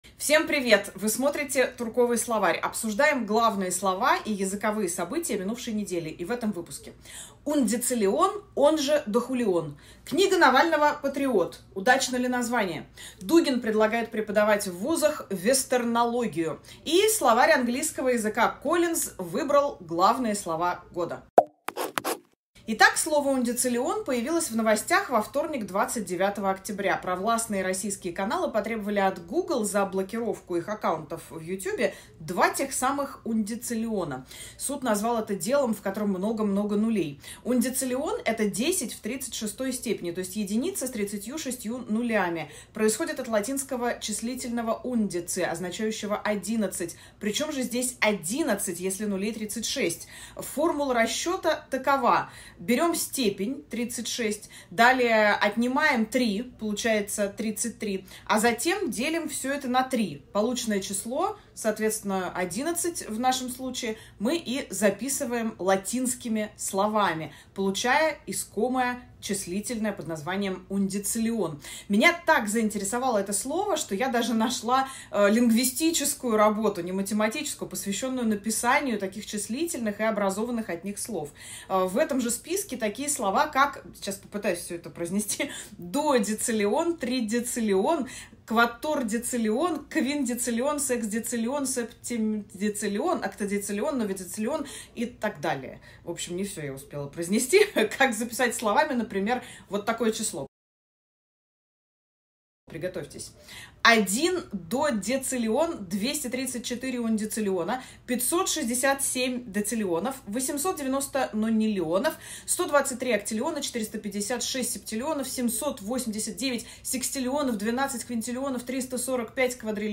Эфир ведёт Ксения Туркова